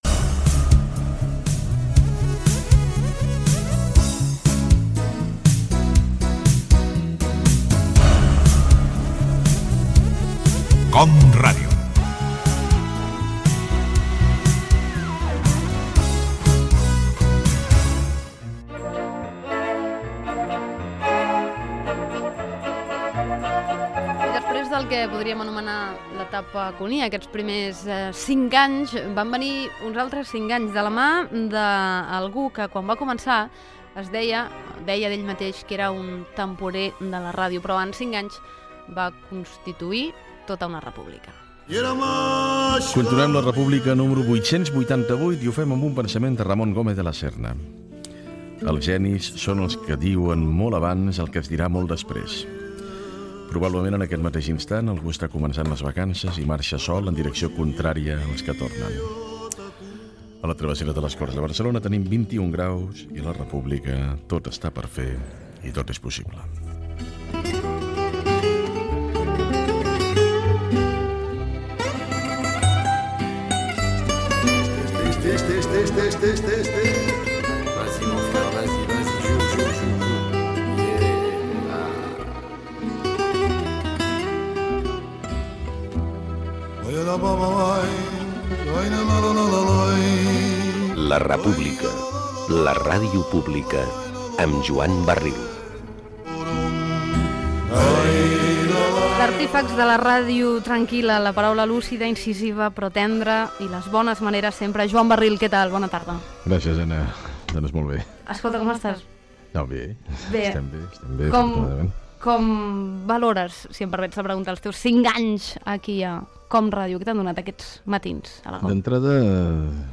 Indicatiu de l'emissora, promoció del programa "La R-pública (La República)" i entrevista al seu presentador Joan Barril.
Entreteniment